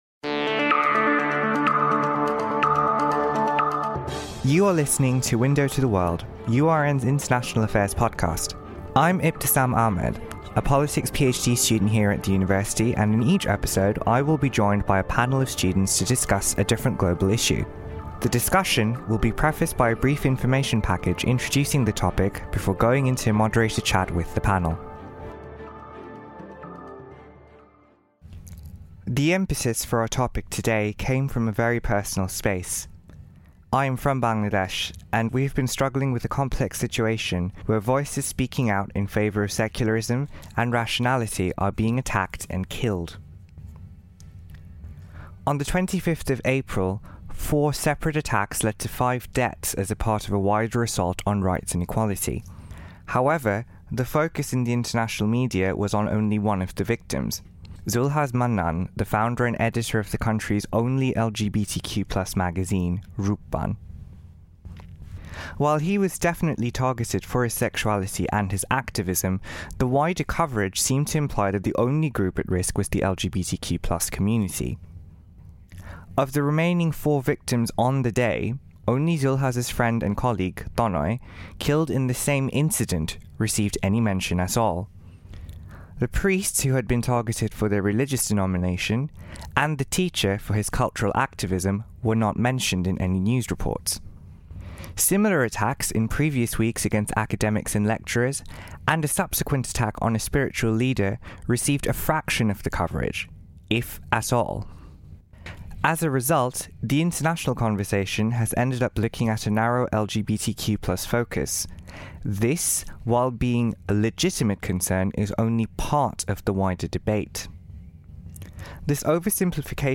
In the first episode of URN's international news podcast, Window to the World, the panellists touch on issues related to the media and its portrayals of global issues. Being influenced by the way the international media has covered the spate of violence in Bangladesh, the discussion touches on who counts as victims, who counts as perpetrators, what the problems of the word "terrorism" are, and how all these discourses have an impact on popular opinion and political reaction.